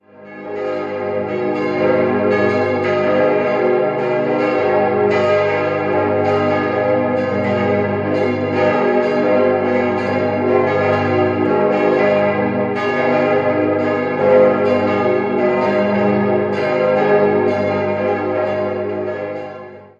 Danach zogen die Katholiken in ihre neue Kirche um. 5-stimmiges Geläute: as°-c'-es'-as'-c'' Alle Glocken wurden in den Jahren 1932/34 von der Gießerei Rüetschi in Aarau gegossen.